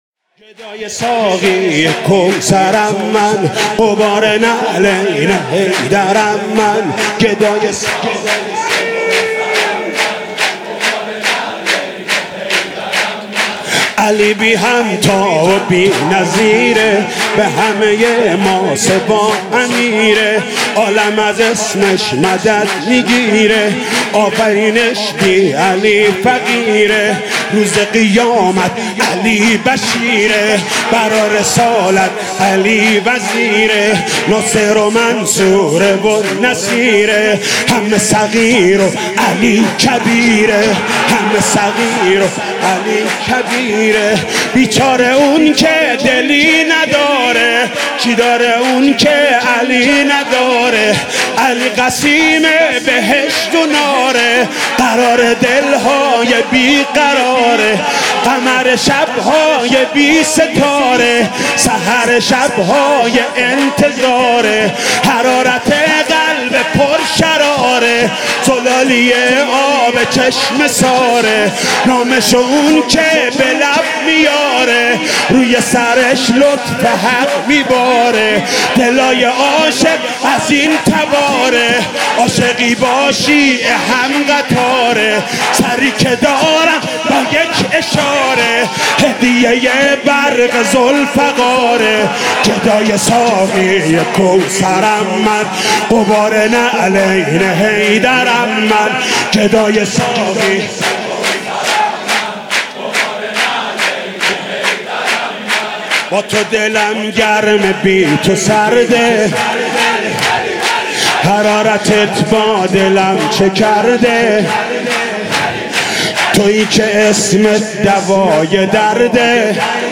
«میلاد امام رضا 1395» سرود: گدای ساقی کوثرم من